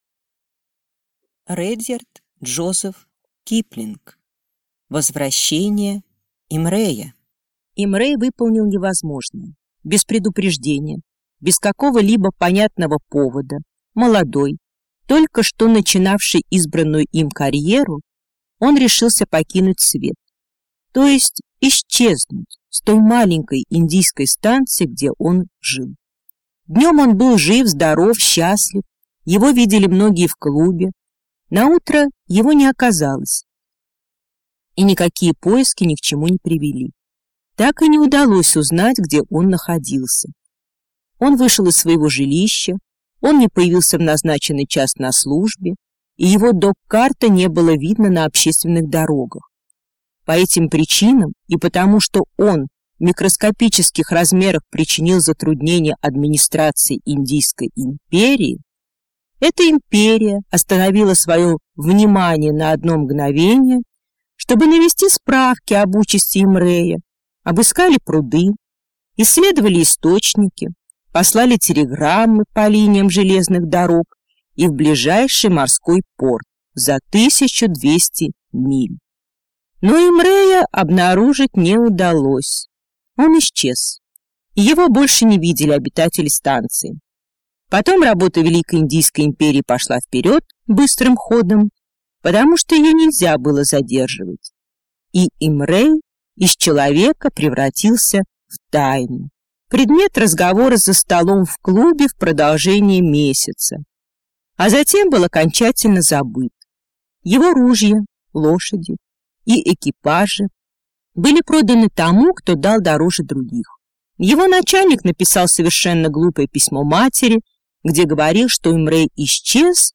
Аудиокнига Возвращение Имрея | Библиотека аудиокниг